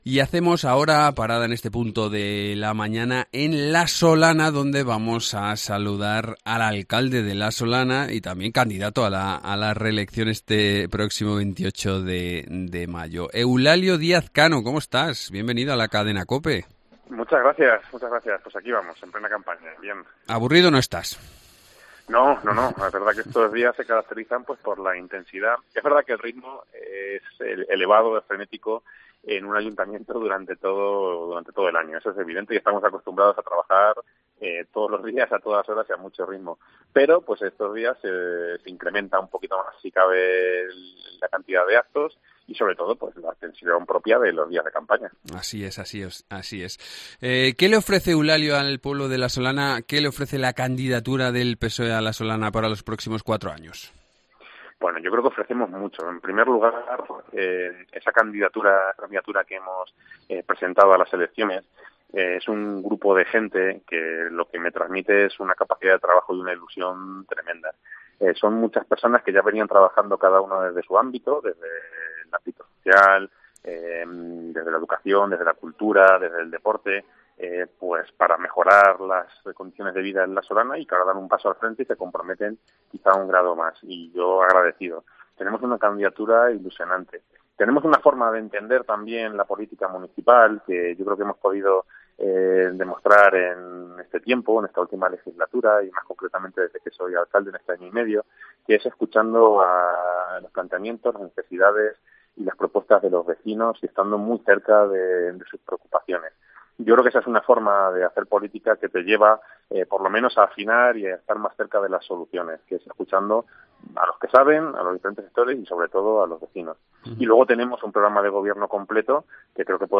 AUDIO: Eulalio Díaz Cano, alcalde de La Solana y candidato a la reelección